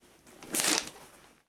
Correr unas cortinas 2
Sonidos: Acciones humanas
Sonidos: Hogar